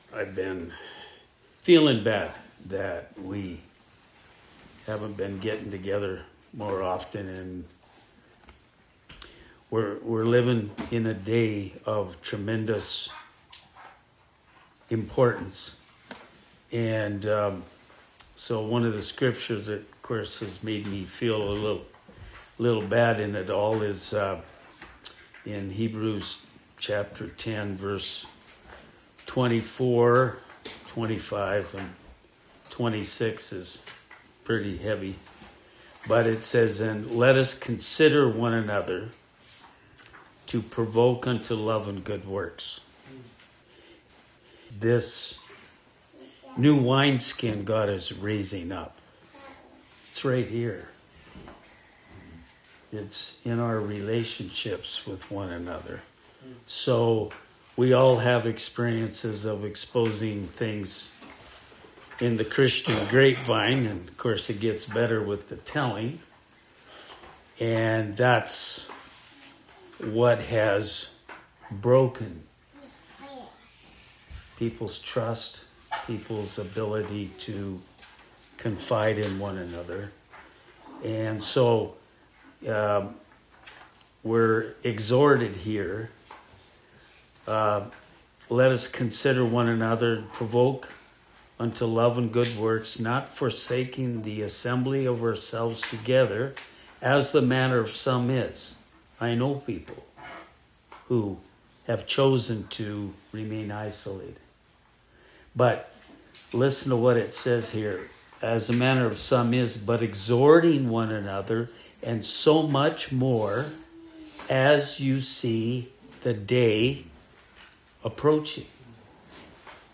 A casual teaching given at a small home fellowship filled with the heart of the Father to see His Church arise into the fullness of His purposes.